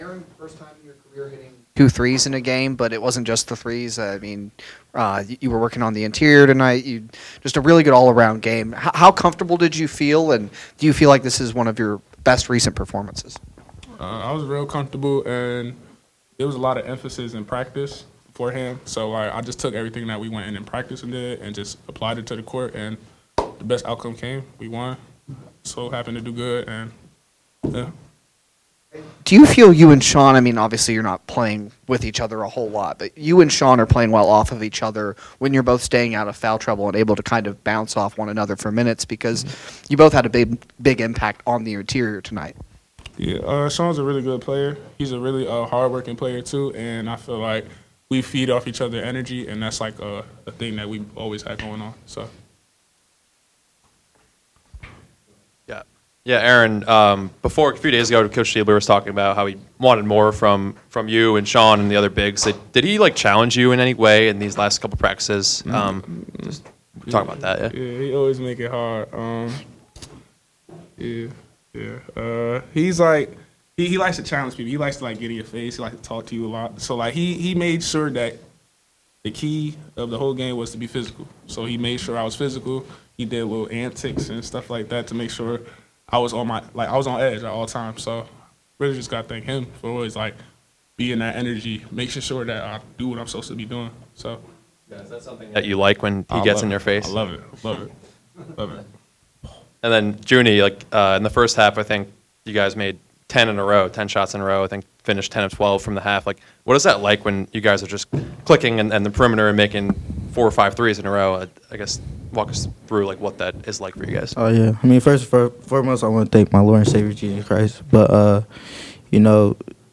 OSU M BKB Postgame Press Conference